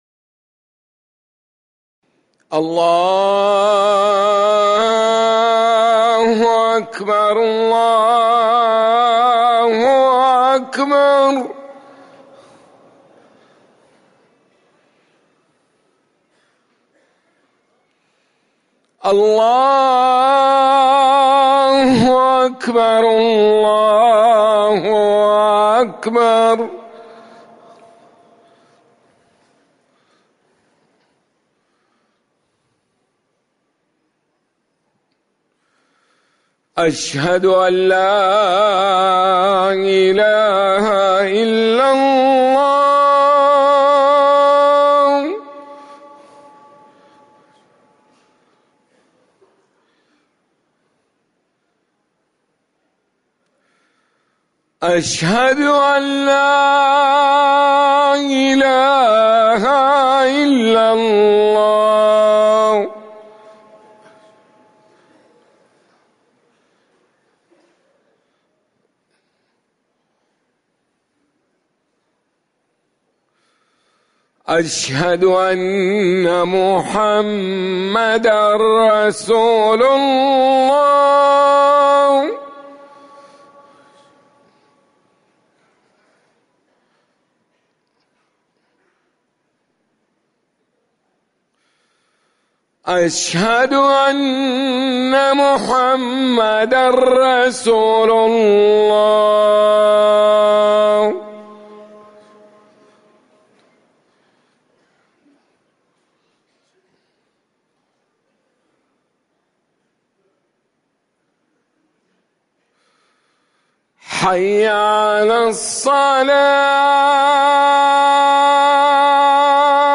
أذان العصر
تاريخ النشر ٢ ربيع الأول ١٤٤١ هـ المكان: المسجد النبوي الشيخ